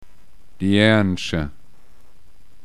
Gemischt Vokale wie ua, üa, ia, iä, ou, usw., sprich mit Betonung beider Vokale.